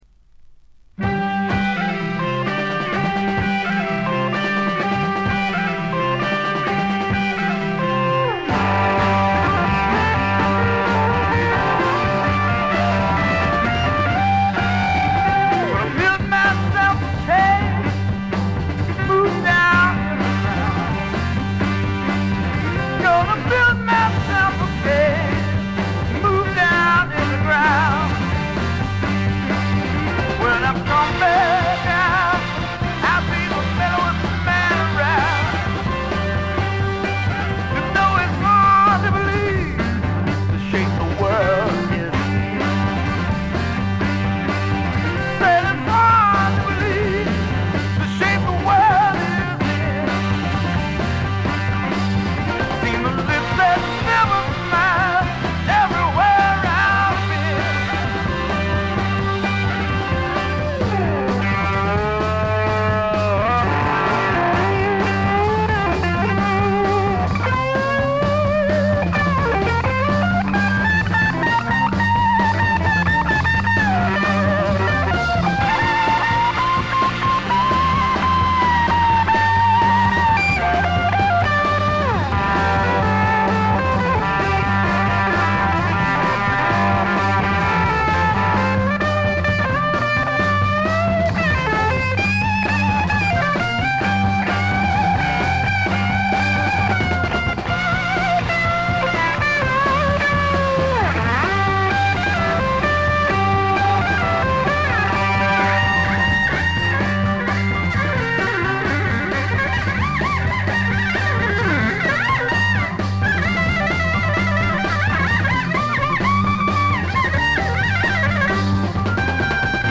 軽微なチリプチ少し。
試聴曲は現品からの取り込み音源です。
Organ, Vocals
Guitar, Electric Sitar
Bass guitar
Tenor Saxophone, Soprano Saxophone, Flute
Percussion